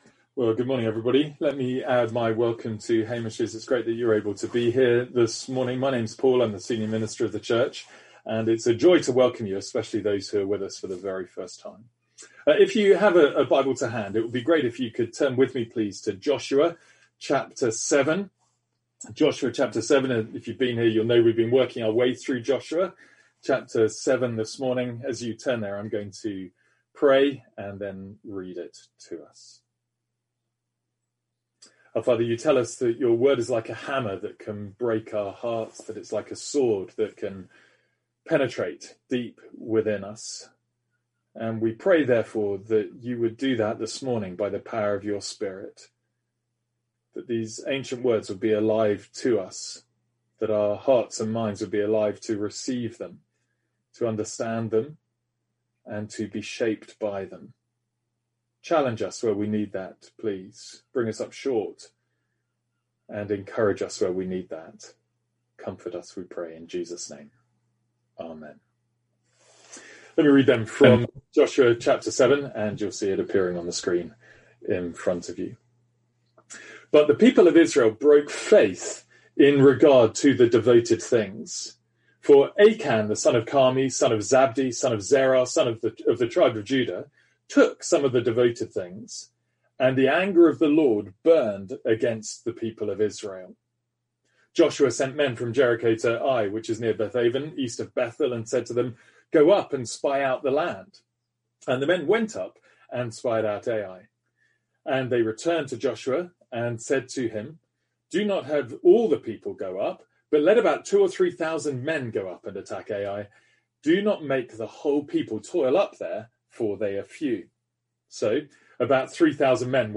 Sermons | St Andrews Free Church
From our morning series in Joshua.